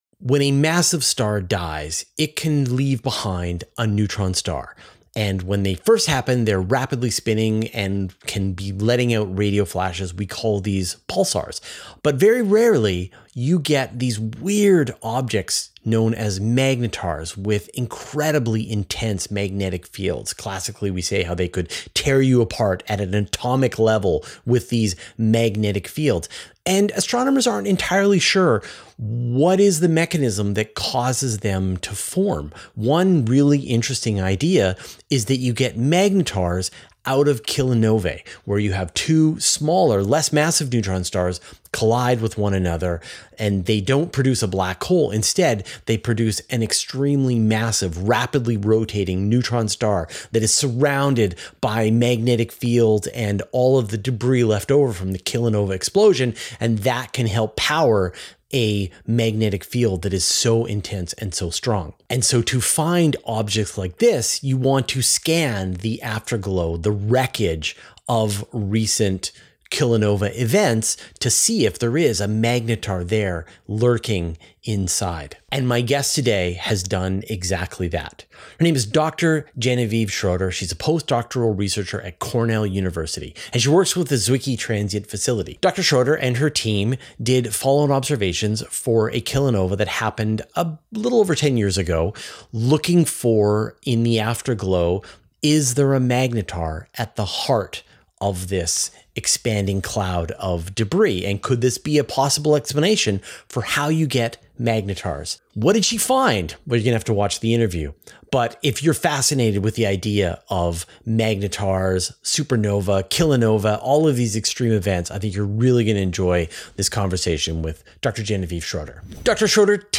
[Interview+] How Magnetars Are Born from Universe Today Podcast | Podcast Episode on Podbay